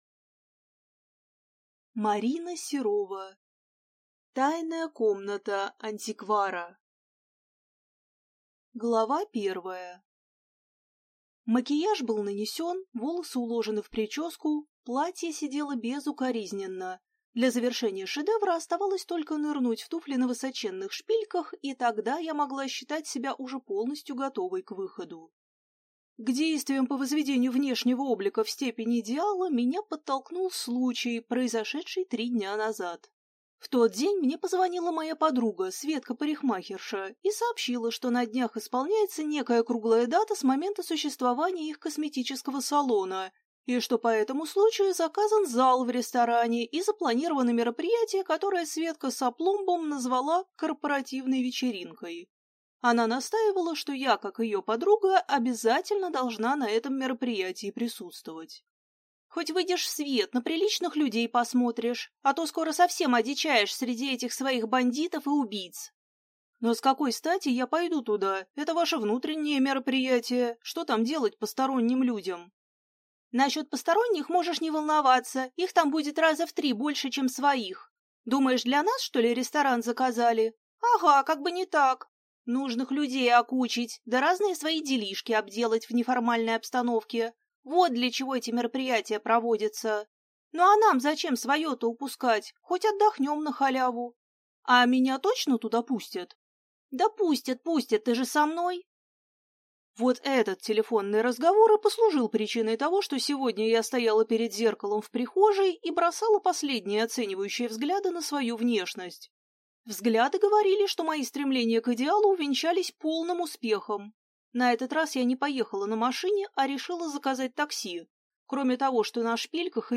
Аудиокнига Тайная комната антиквара | Библиотека аудиокниг